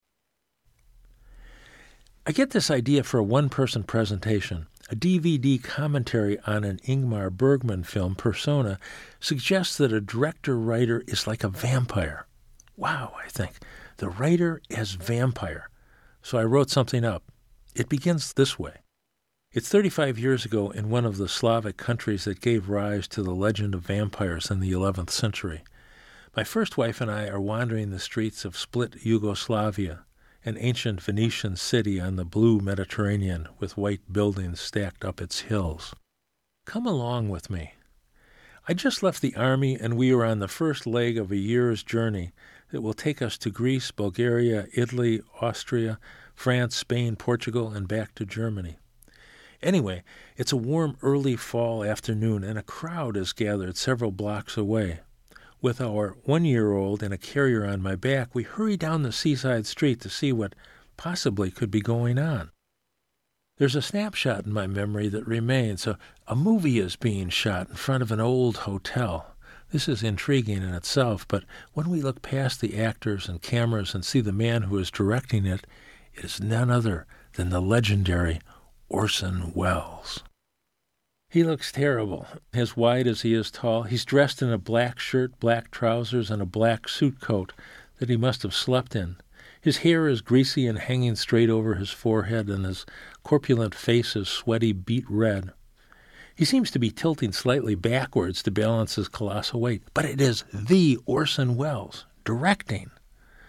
went into a recording studio